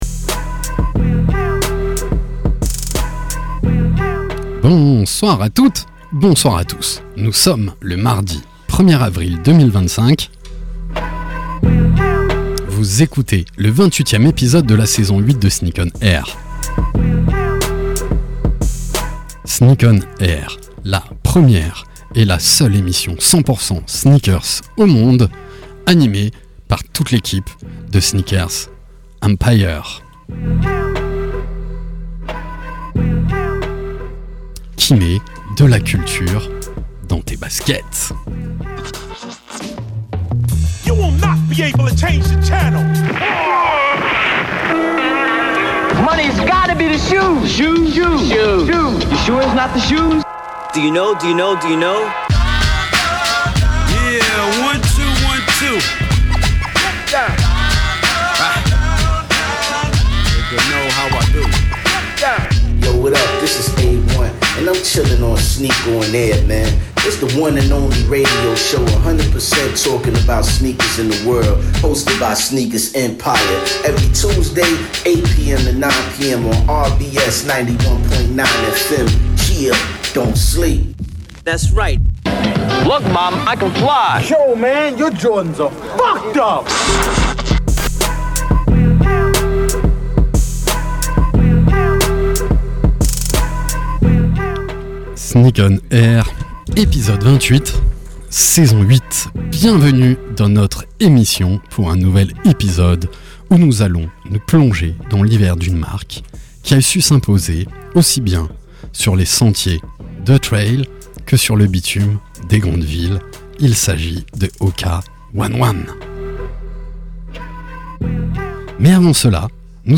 Sneak ON AIR, la première et la seule émission de radio 100% sneakers au monde !!! sur la radio RBS tous les mardis de 20h à 21h.
Actu sneakers, invités, SANA, talk.